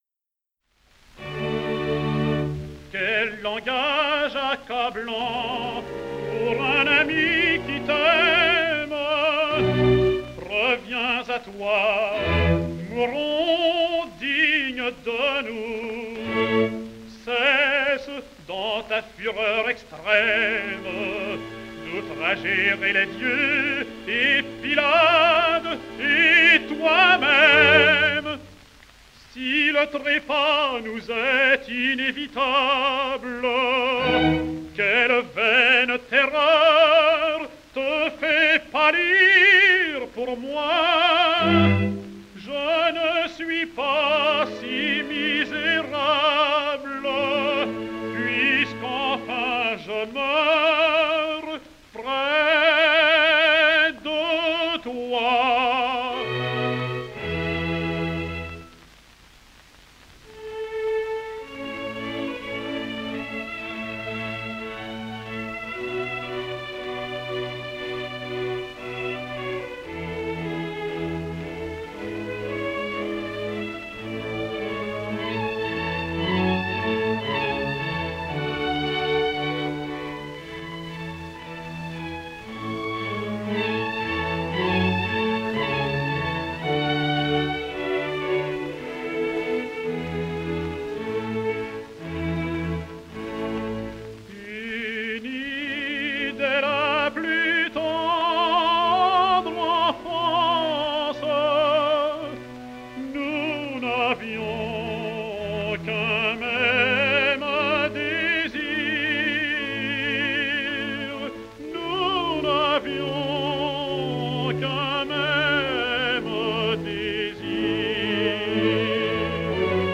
Georges Thill sings Iphigénie en Tauride: